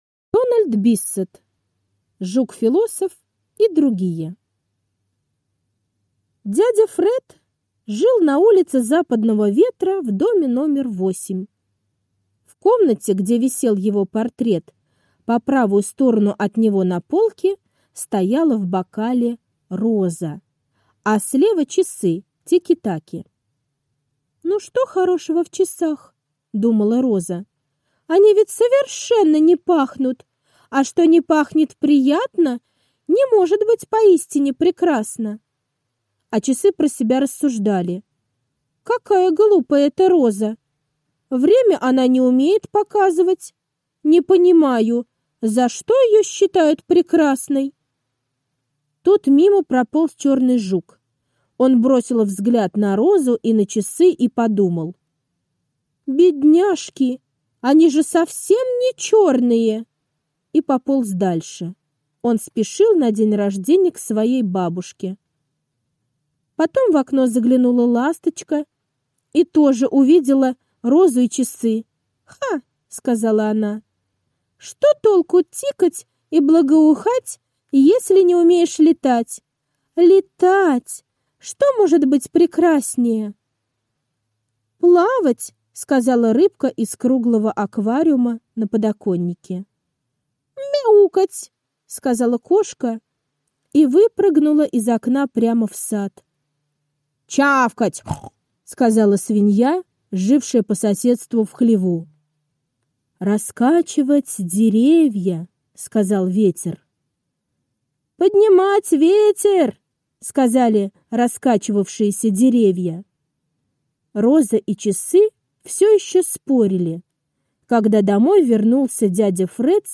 Жук-философ и другие - аудиосказка Дональда Биссета. Сказка рассказывает о том, что каждый понимает красоту по-своему.